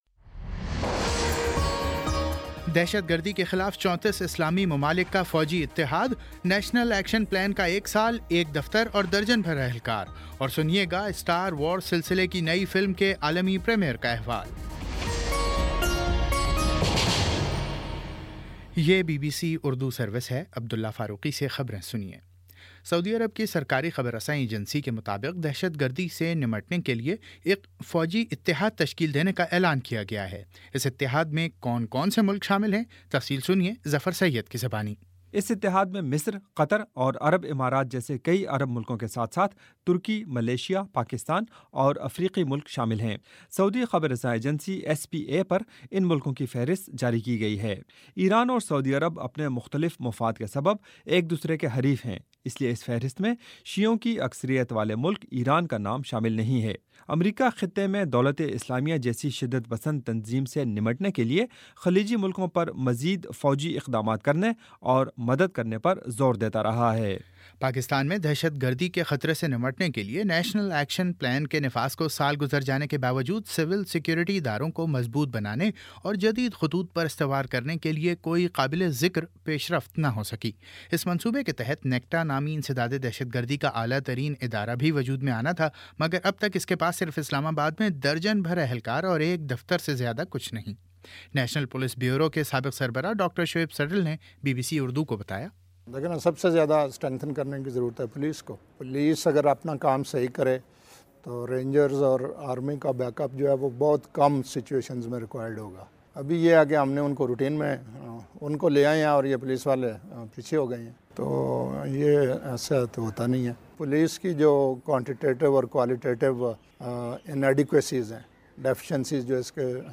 دسمبر 15 : شام پانچ بجے کا نیوز بُلیٹن